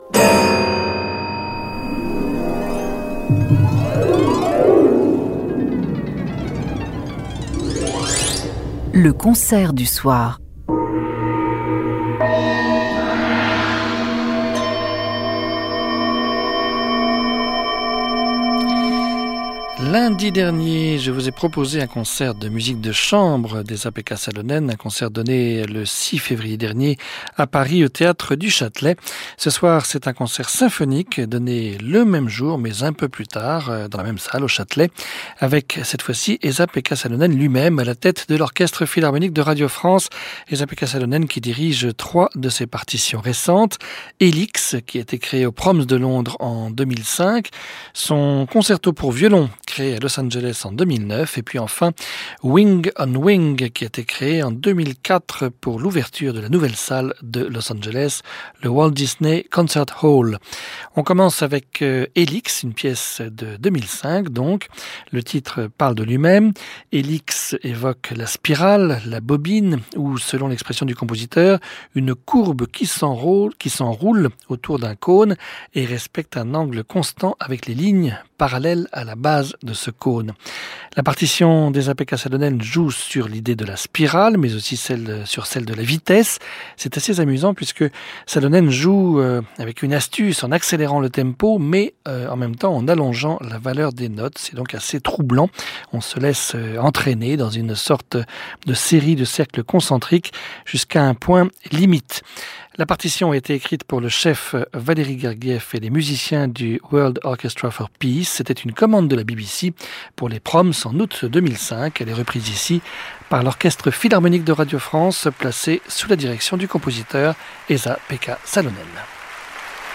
sopranos
violin
In concert from Paris.